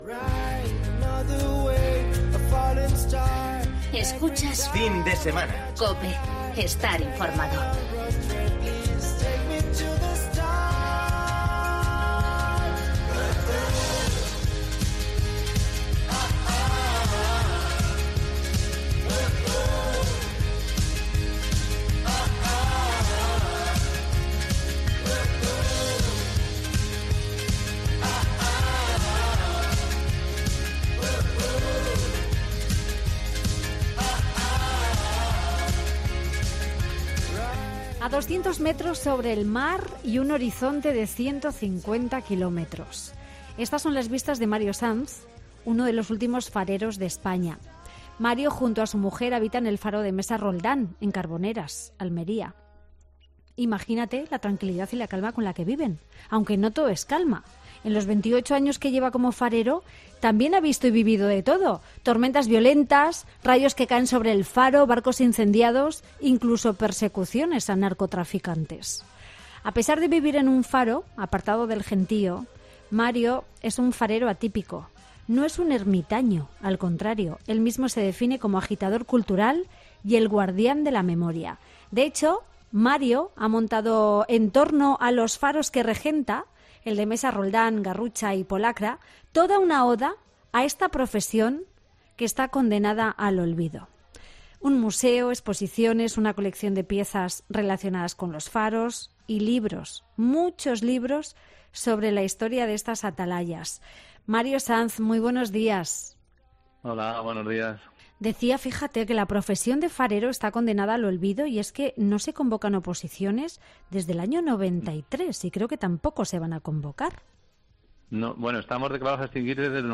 para reportajes y entrevistas en profundidad